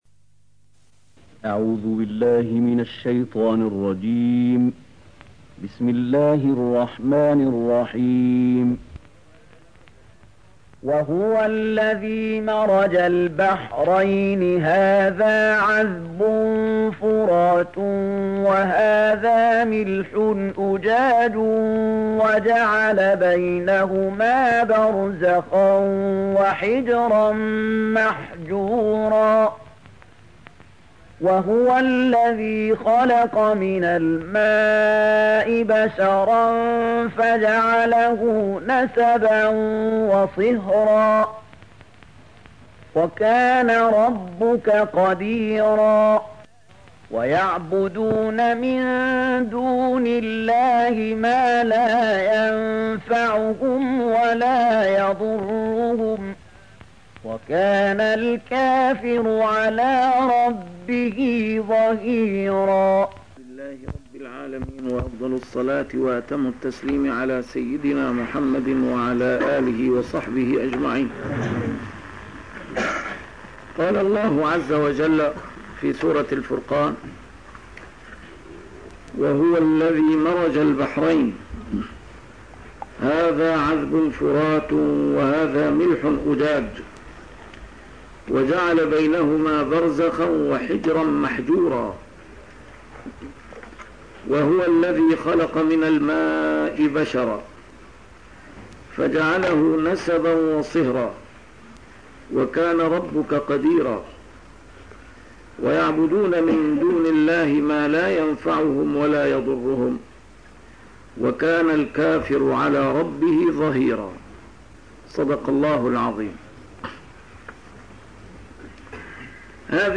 A MARTYR SCHOLAR: IMAM MUHAMMAD SAEED RAMADAN AL-BOUTI - الدروس العلمية - تفسير القرآن الكريم - تسجيل قديم - الدرس 215: الفرقان 53-55